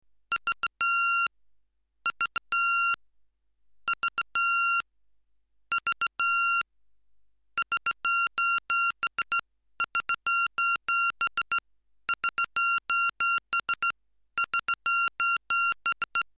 Oder kann so ein Soundkarten-Oszi überhaupt Rechtecksignale (hier etwa 1 KHz - Grundschwingung) darstellen?
Und so piept es
S O S.